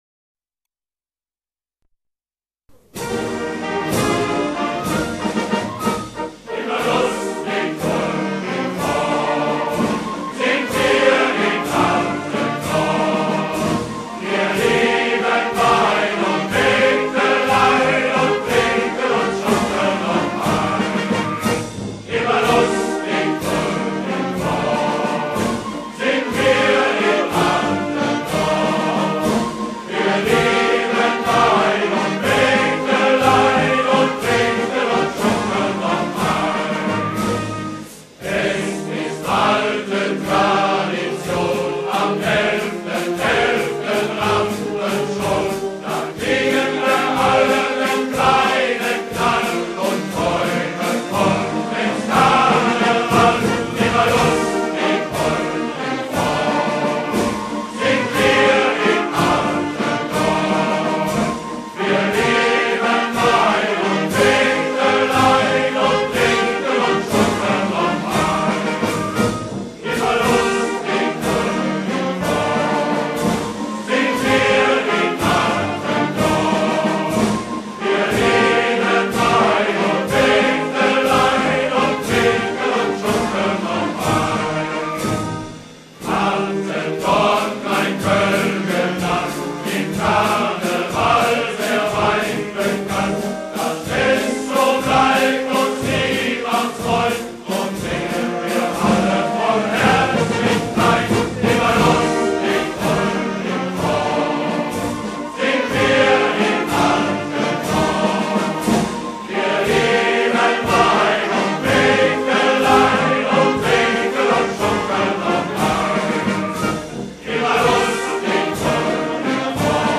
Hier geht es zum Attendorner Karnevalsschlager: